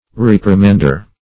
\Rep"ri*mand`er\ (-m?nd`?r)
reprimander.mp3